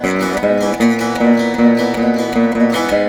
154C VEENA.wav